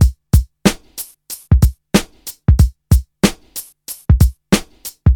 • 93 bpm boom bap rap drum beat C.wav
This drum loop hits lound a clear and can fit well in a quite busy hip-hop mix. Pro tip: try it with a bassline which has C as a root key as the bass drum is in C.
93_bpm_boom_bap_rap_drum_beat_C_GPj.wav